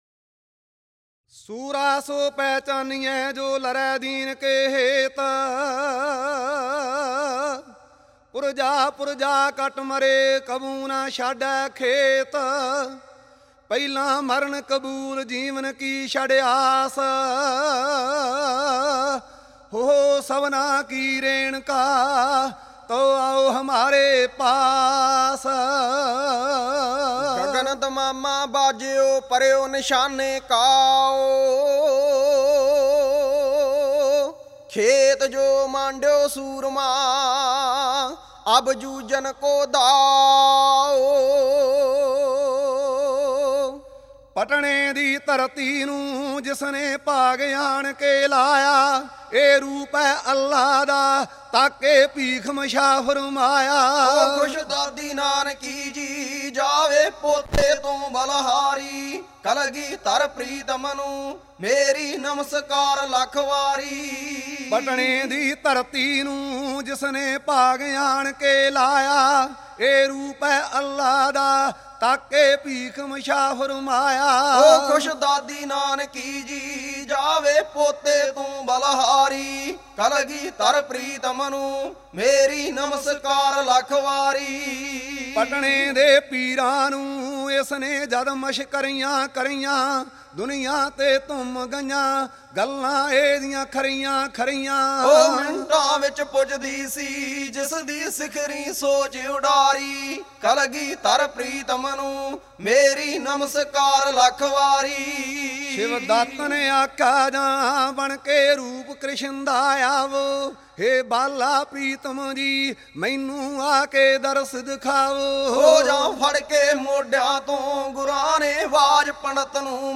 Genre: Kavishri